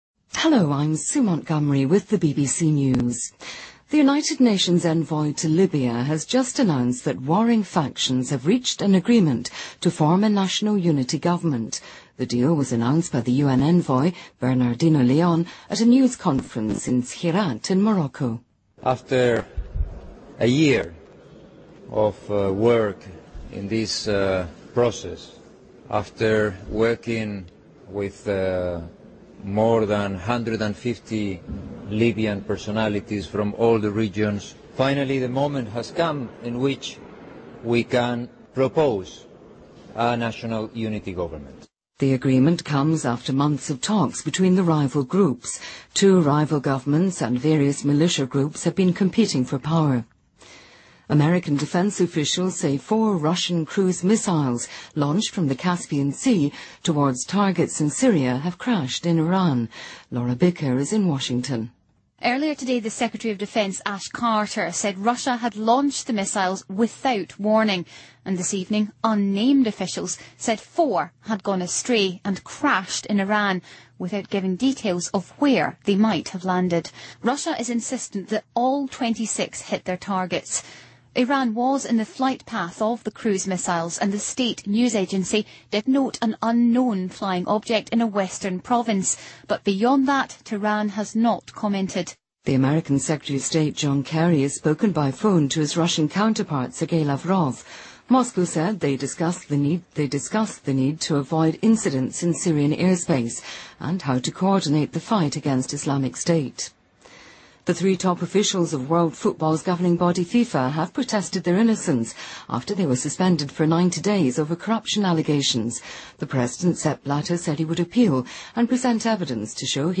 BBC news:赛琳娜.戈麦斯被诊断患有自身免疫性疾病红斑狼疮|BBC在线收听